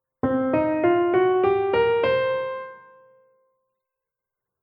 Tonleitern (basics) → Blues-Tonleiter - Musikschule »allégro«
Kehren wir zunächst zur Variante von c beginnend zurück:
TonleiternBlues.mp3